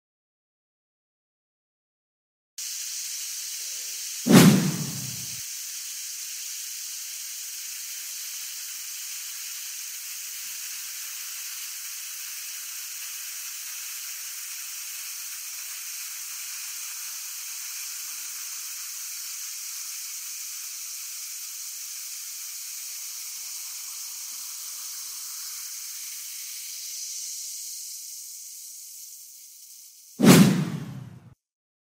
Burning Pencil ✏ Experiment 🔬 Sound Effects Free Download